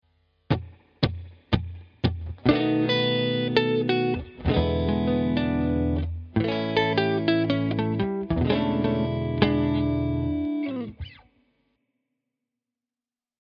blues-issue132-ex4.mp3